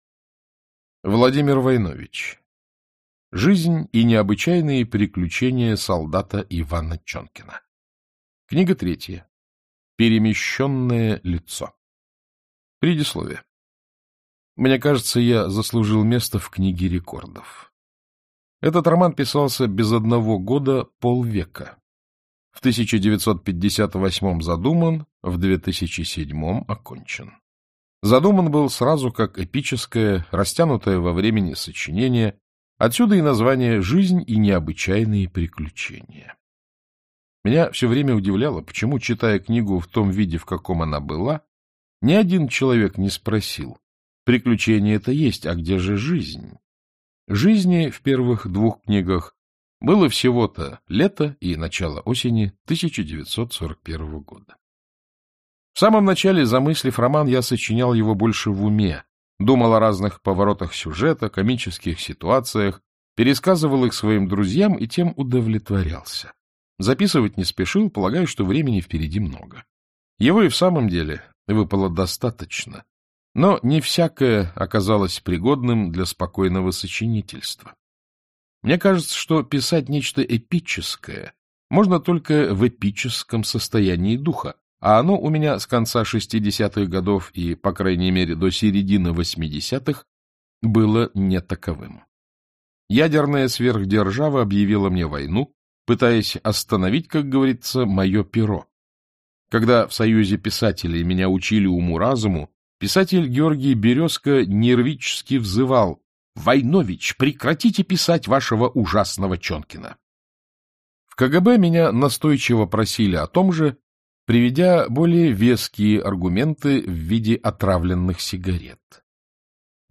Аудиокнига Жизнь и необычайные приключения солдата Ивана Чонкина. Книга третья. Перемещенное лицо | Библиотека аудиокниг
Перемещенное лицо Автор Владимир Войнович Читает аудиокнигу Александр Клюквин.